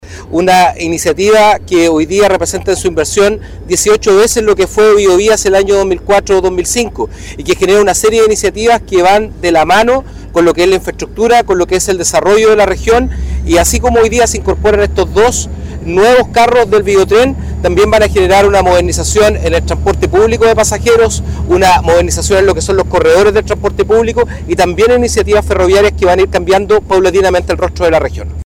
El seremi de Transportes y Telecomunicaciones del Biobío, Patricio Fierro, dijo que “son obras que mejoran la calidad de vida de las personas y de las familias que a diario ocupan el Biotren, pero además destacar lo que ha sido un instrumento de planificación en el desarrollo de la región y que esperamos también sea un instrumento que se mantenga en el tiempo, como son las obras que están contempladas en el Plan Más Movilidad”.